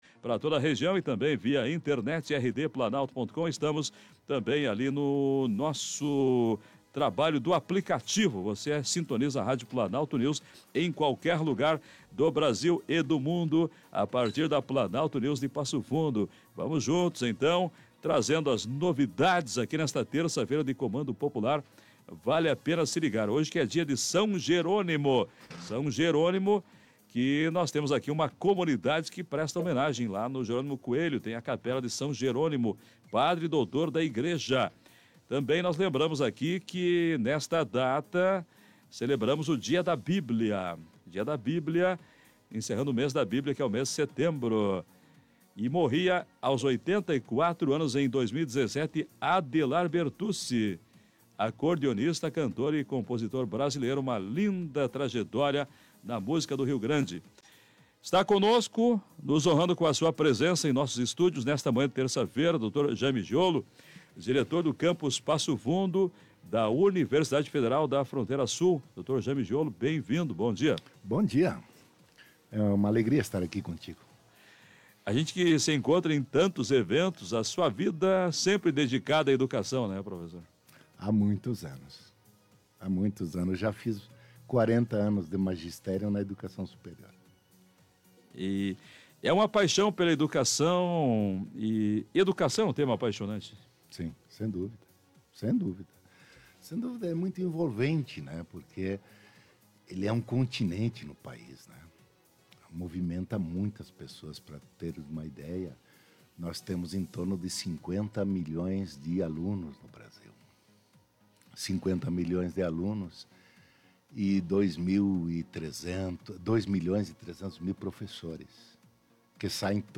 ENTREVISTA-UFFS-30-09.mp3